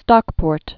(stŏkpôrt)